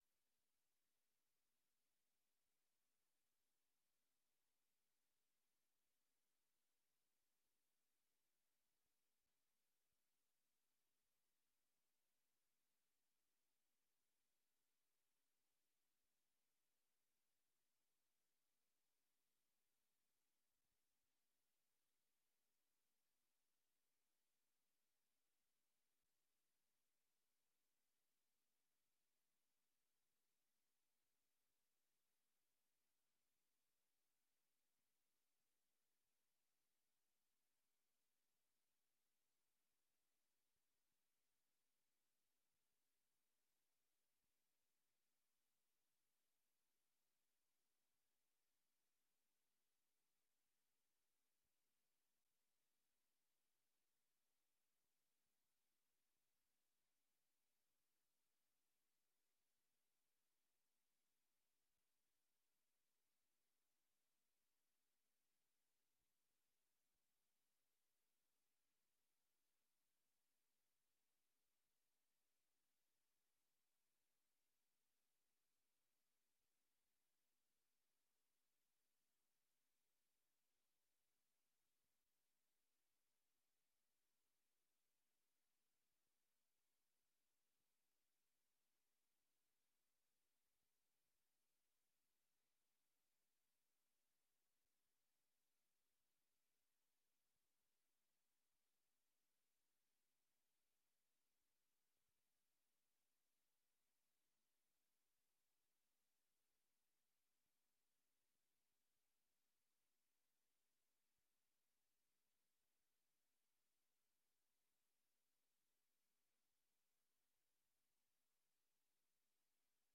Raadsvergadering 09 maart 2023 19:30:00, Gemeente Dronten
Locatie: Raadzaal